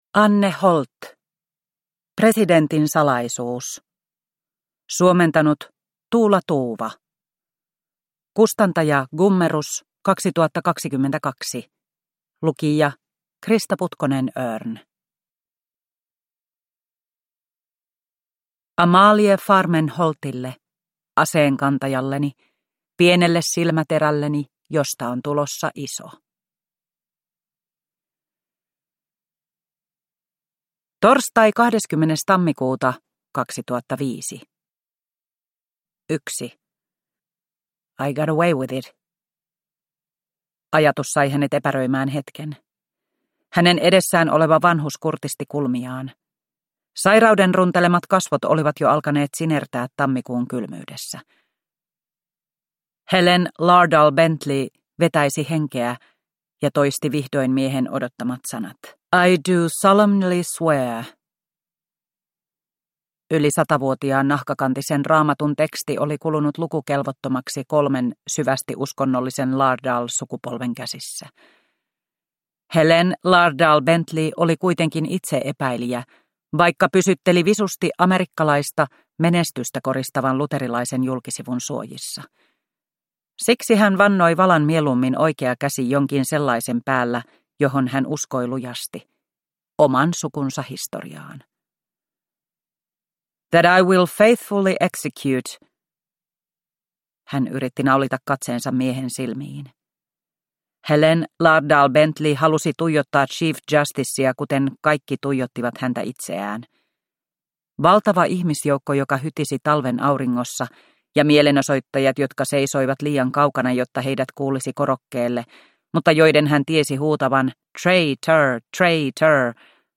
Presidentin salaisuus – Ljudbok – Laddas ner